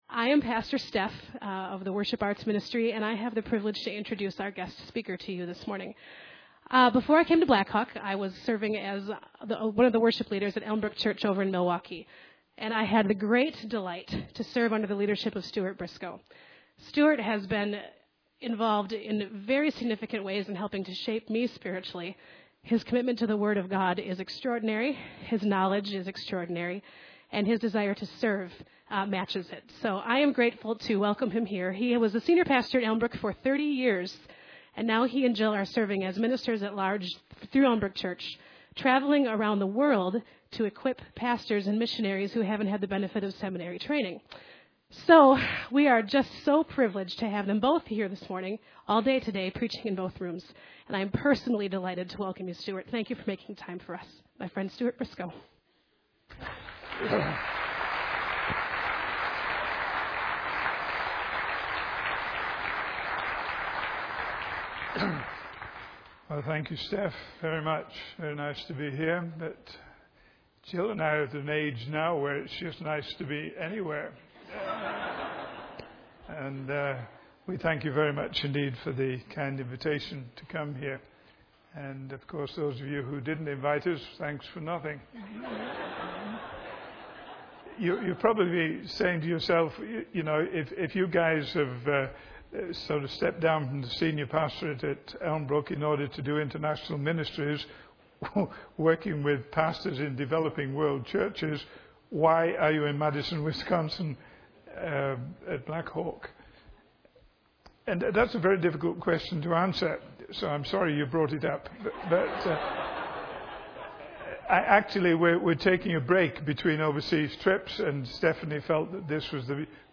In this sermon, the speaker emphasizes the importance of recognizing the creator through the things that are created. He uses examples of music, paintings, and books to illustrate how we can gain insight into the creator through his creations.